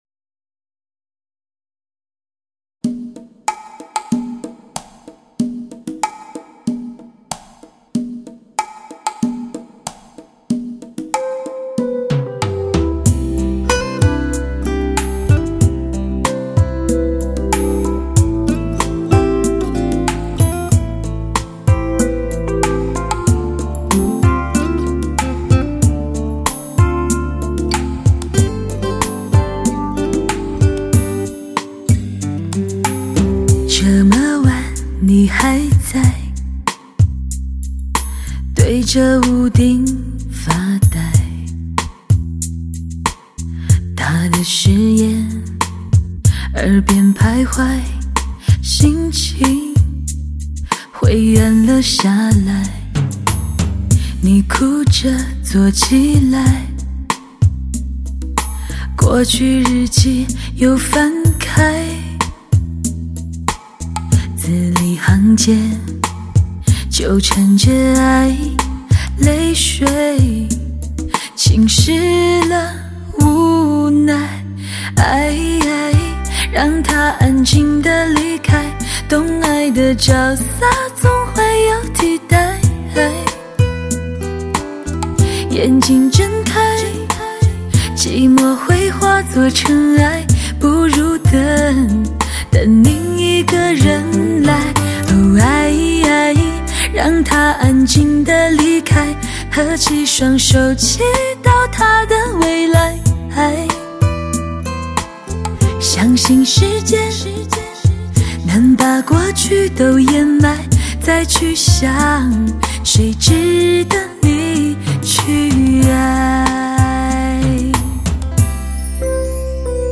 恬静的淡然，磁性略带暗哑，时而又漂浮着甘醇的嗓音，别样质感细细品味